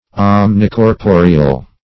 Search Result for " omnicorporeal" : The Collaborative International Dictionary of English v.0.48: Omnicorporeal \Om`ni*cor*po"re*al\, a. [Omni- + corporeal.] Comprehending or including all bodies; embracing all substance.
omnicorporeal.mp3